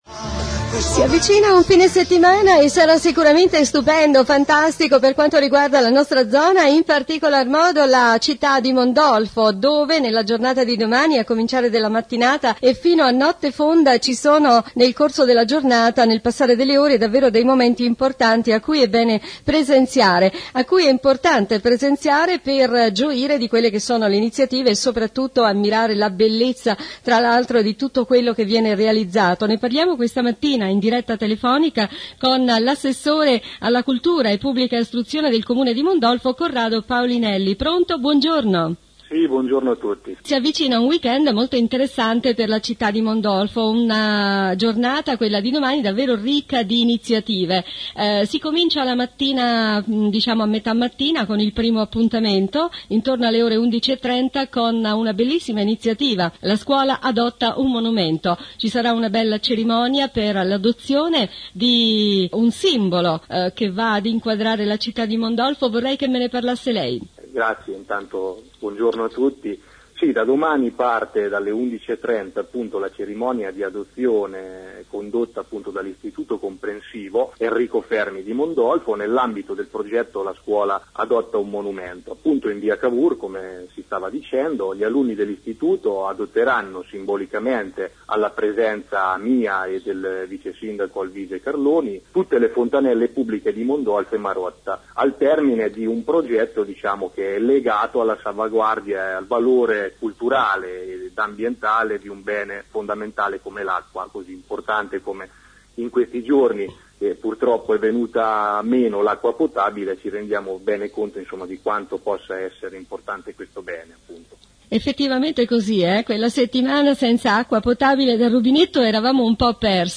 Ascolta intervista Assessore alla Pubblica Istruzione – Politiche giovanili e per l’Infanzia – Comunicazione e stampa – Decentramento – Rapporti con i cittadini – Accoglienza- Cultura – Gemellaggi Signor CORRADO PAOLINELLI
intervista-CORRADO-PAOLINELLI.mp3